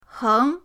heng2.mp3